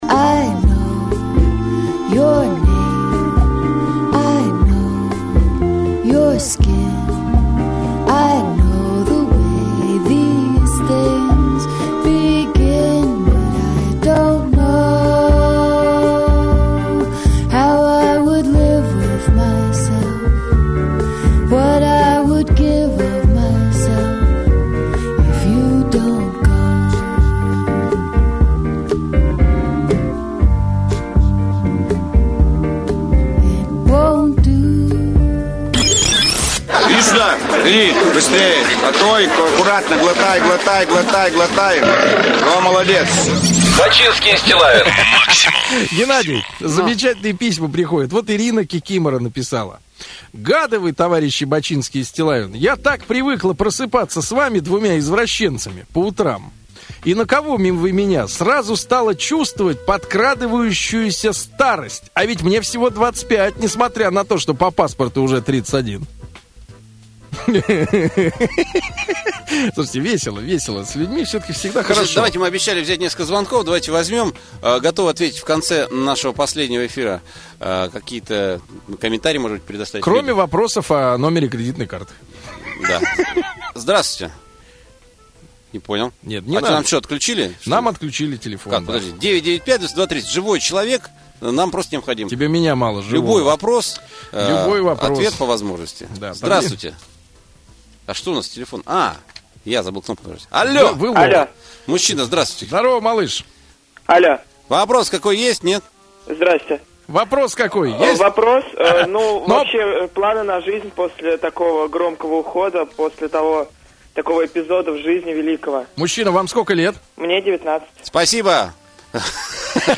Эта запись, последний "выход" их 29 июня 2007 года в эфир "Максимум". Бачистил прощались. Слушается трогательно, хотя и слышно, что внутри у ребят истерика.
| Теги: Бачинский, Бачинский и Стиллавин, диджей, запись эфира, последний эфир, радио максимум, утреннее шоу